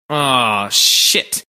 Нежелательное Сообщение "Ohh Shit!"